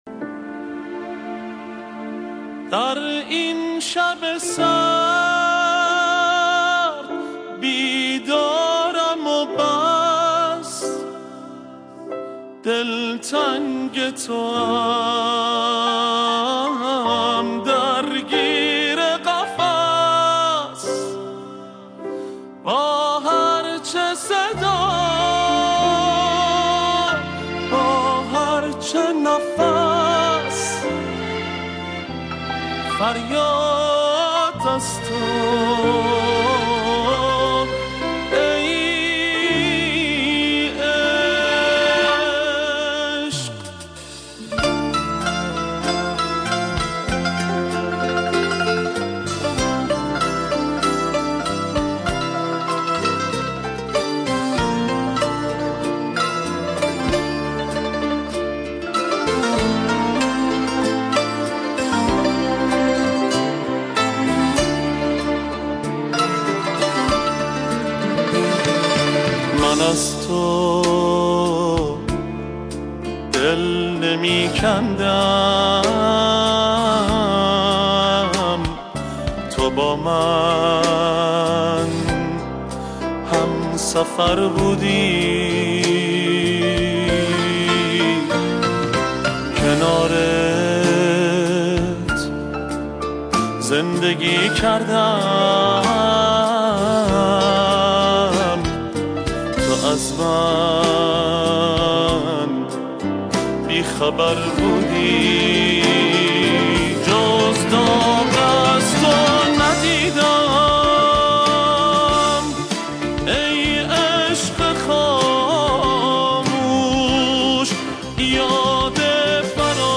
دسته بندی : دانلود آهنگ غمگین تاریخ : چهارشنبه 6 فوریه 2019